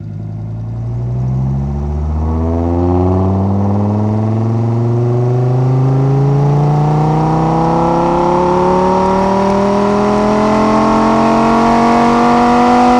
rr3-assets/files/.depot/audio/Vehicles/v10_02/v10_02_accel.wav
v10_02_accel.wav